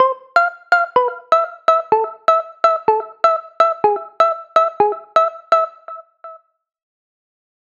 Как накрутить этот плак?